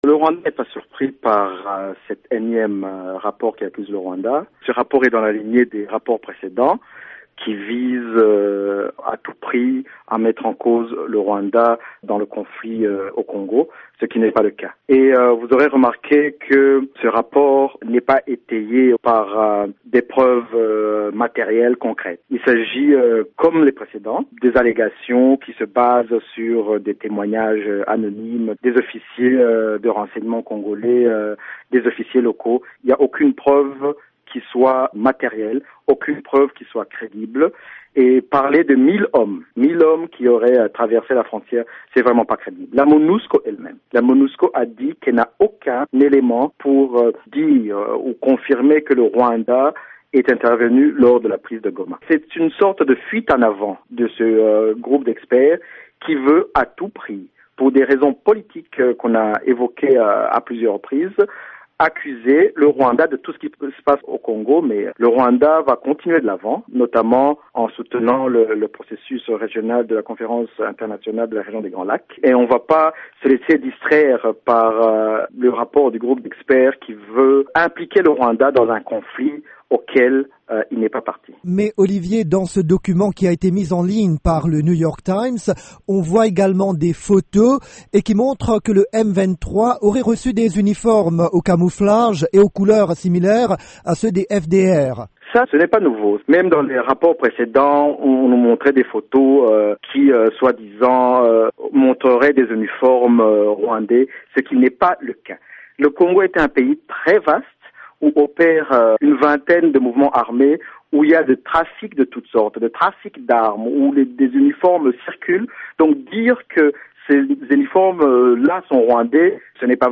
Olivier Nduhungirehe, Conseiller du Rwanda auprès de l’ONU